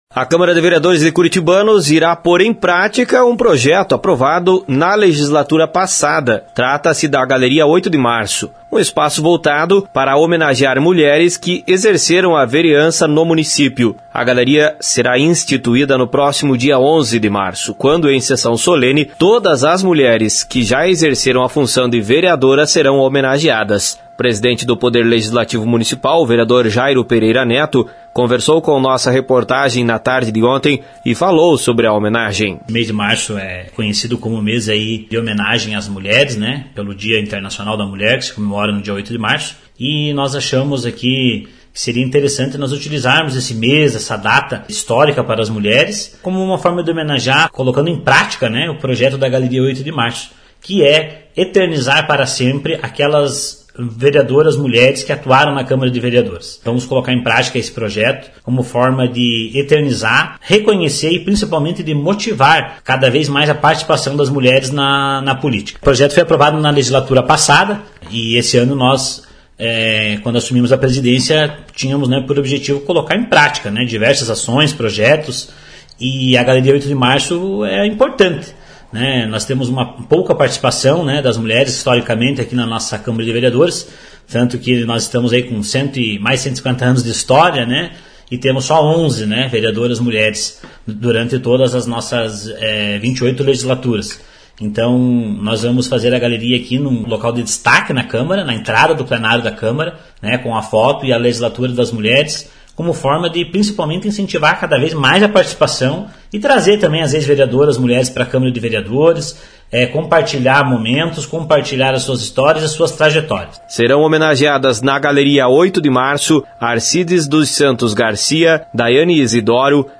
Informações com o repórter